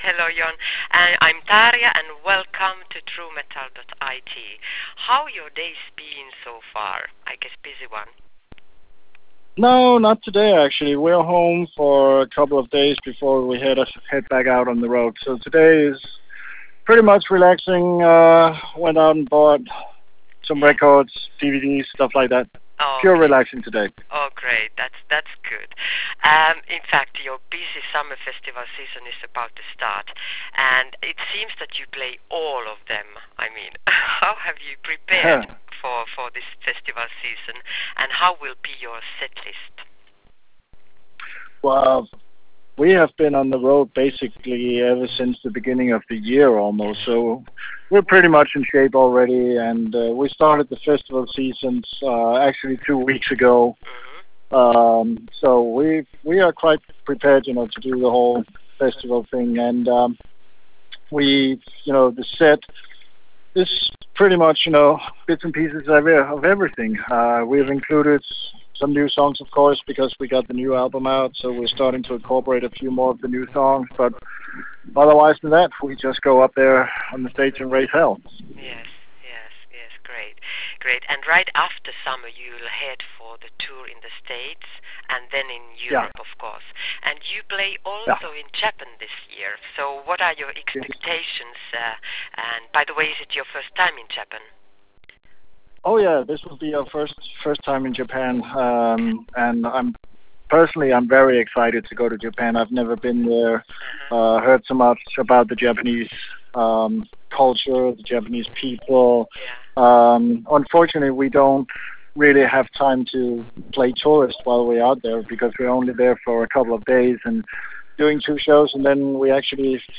Audio Interview: Jon Larsen From VOLBEAT Talks About Latest Album, Summer Festivals And Upcoming Shows